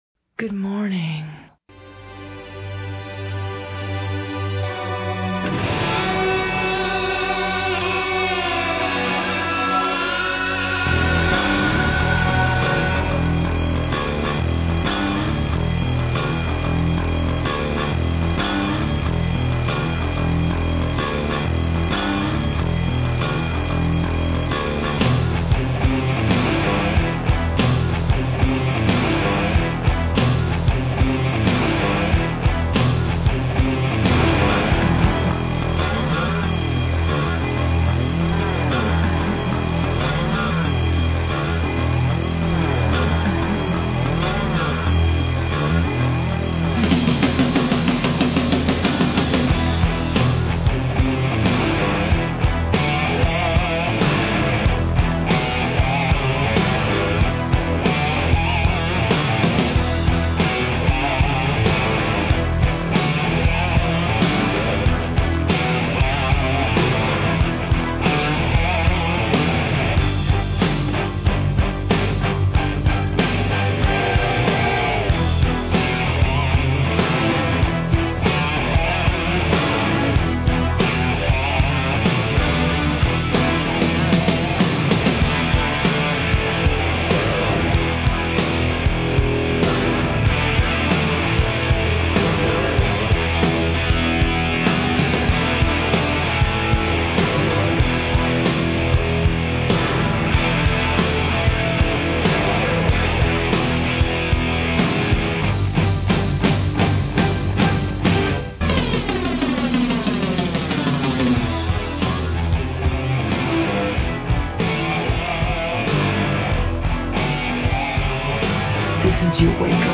.MP3    (MPEG 2.5 layer 3, 24KB per second, 11,025 Hz, Mono)
Rock